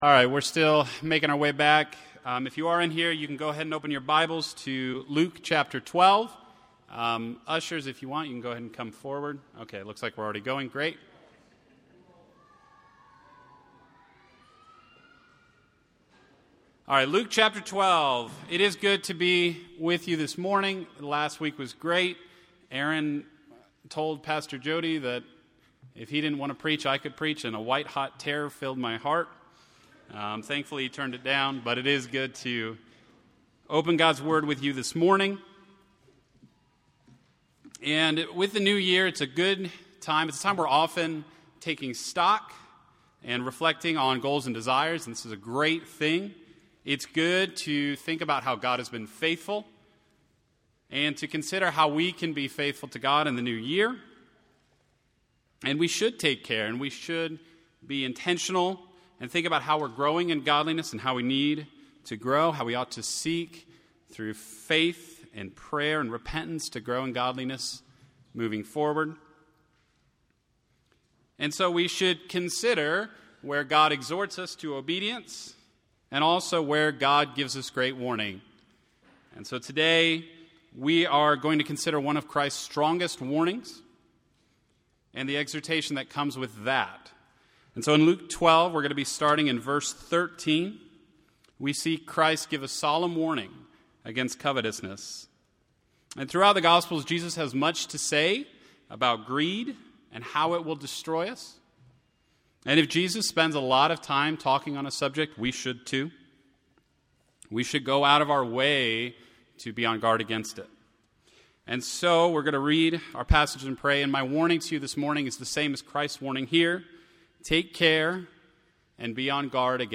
Bloomington Bible Church Sermons